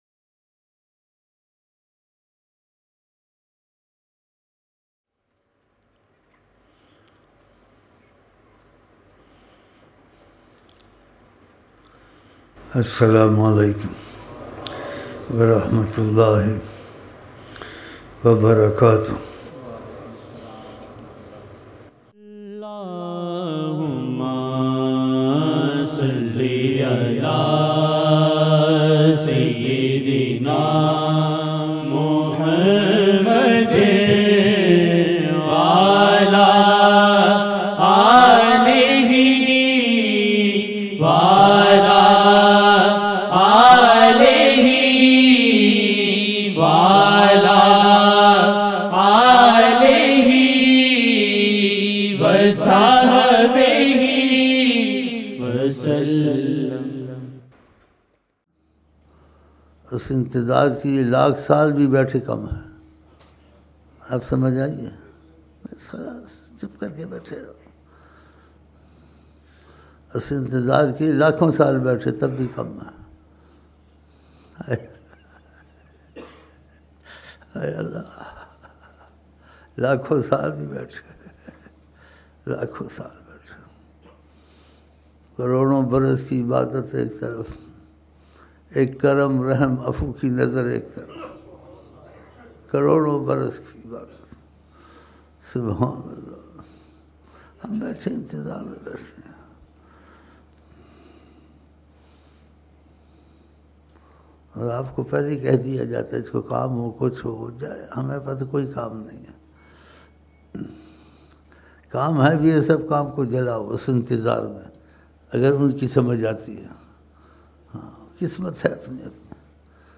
11-March-2008 Fajar Mehfil